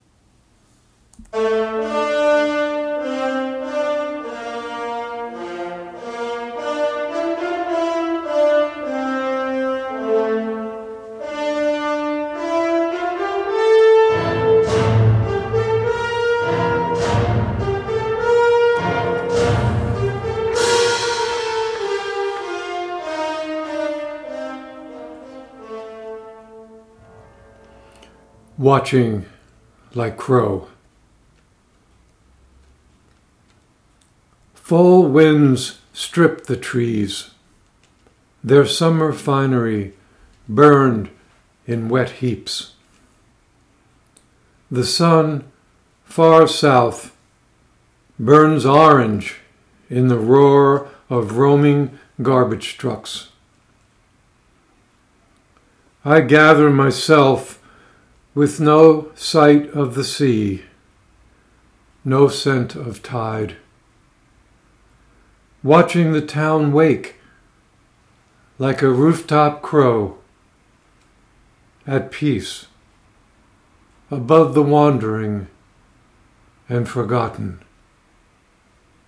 Reading of “Watching Like Crow” with music by Gustav Mahler